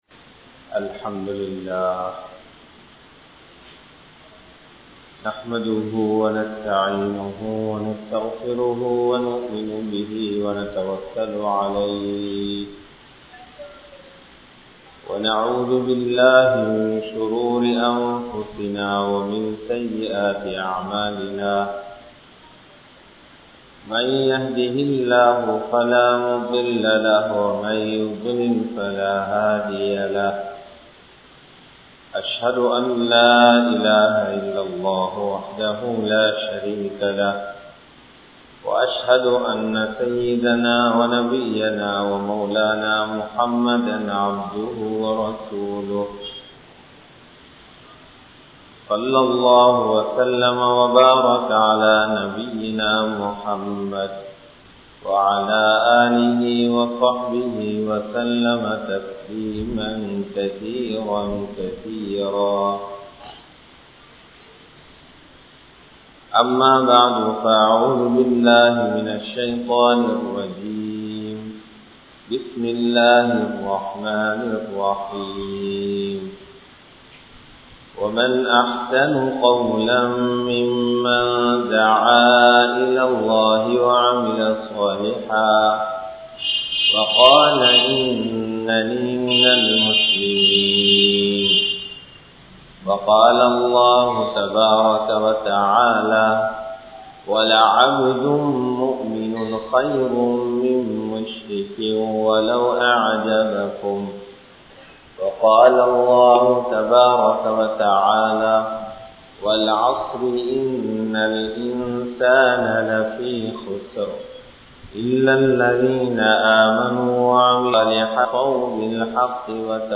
Vaalifan Entraal Yaar? (வாலிபன் என்றால் யார்?) | Audio Bayans | All Ceylon Muslim Youth Community | Addalaichenai